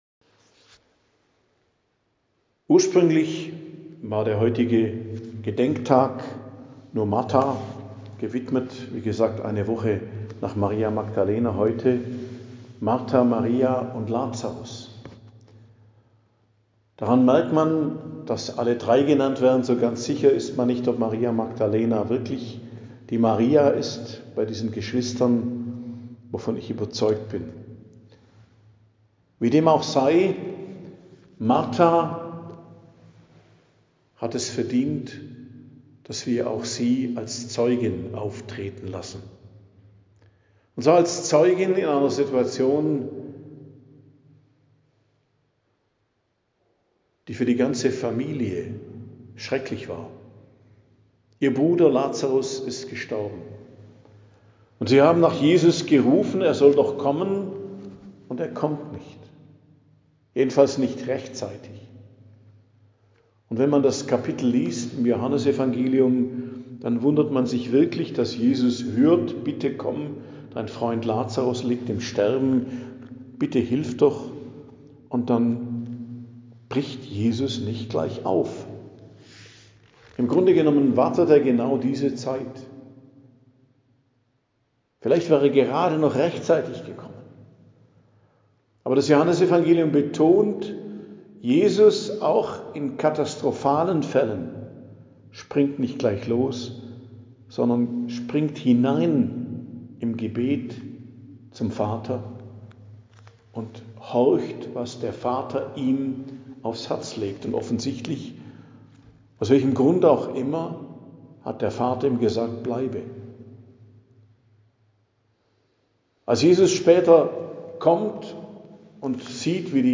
Predigt am Gedenktag der Hll Marta, Maria und Lazarus, 29.07.2025